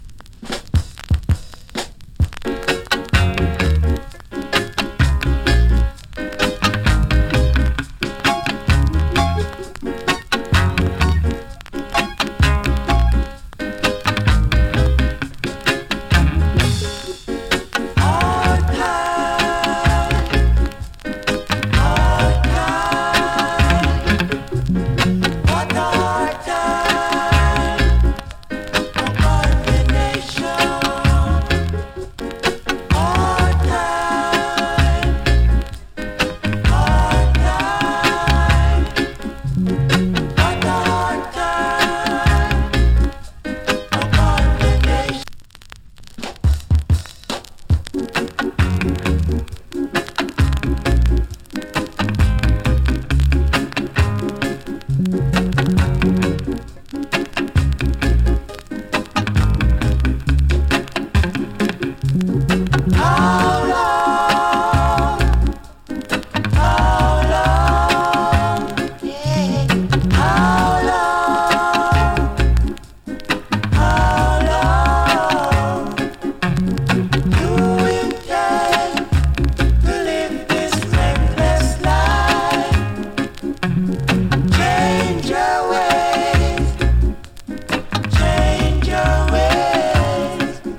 の EARLY REGGAE !!